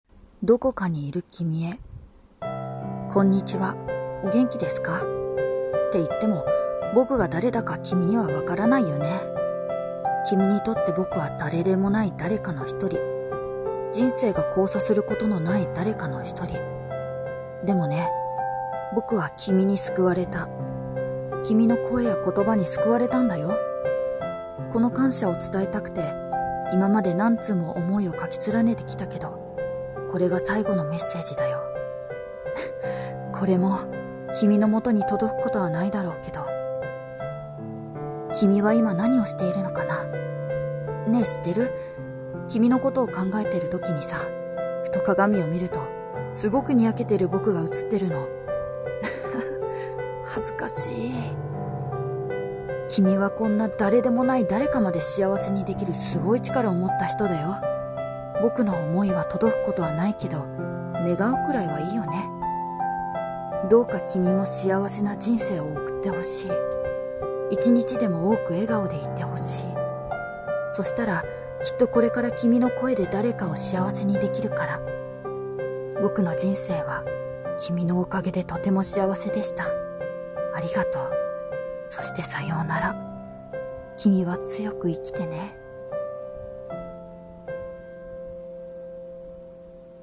【朗読台本】届かない手紙